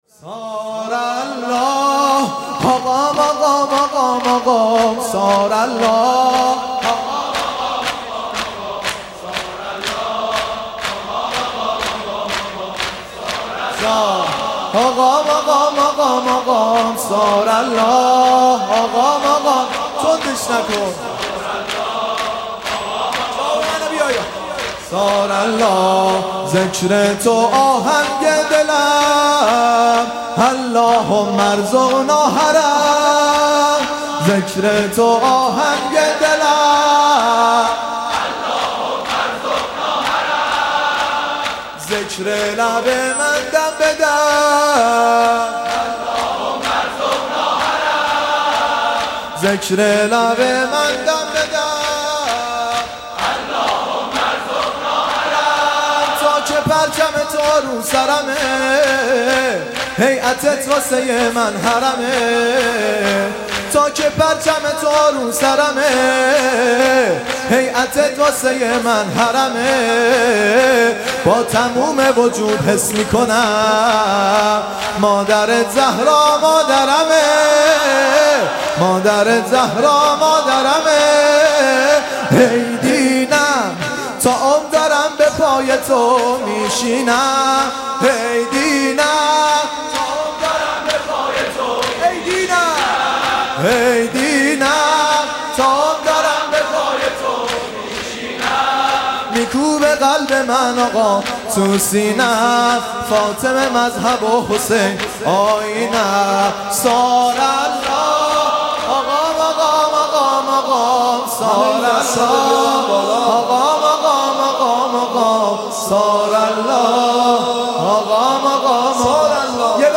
دفتر زیارتی نیایش سیر قزوین- مداحی